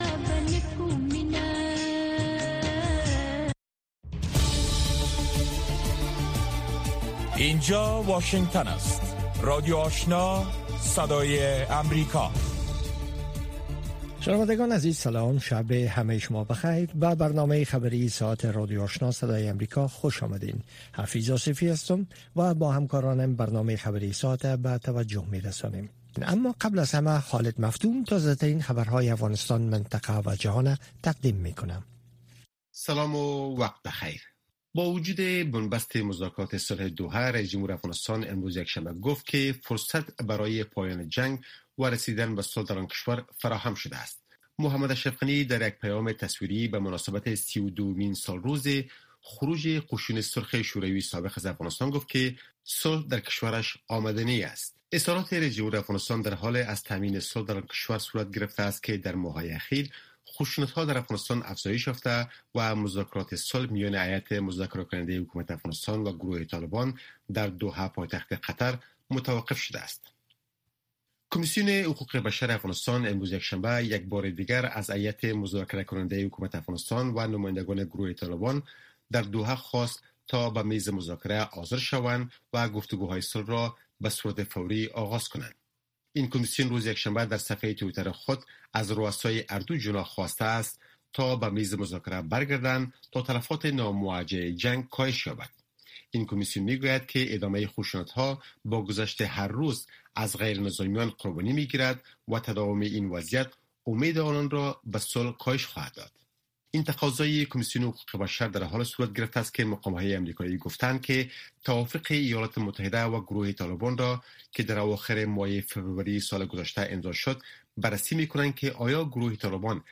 گفت و شنود بحث رادیویی است که در آن موضوعات مهم خبری با حضور تحلیلگران و مقام های حکومت افغانستان به بحث گرفته می شود. گفت و شنود به روزهای سه شنبه و جمعه به ترتیب به مسایل زنان و صحت اختصاص یافته است.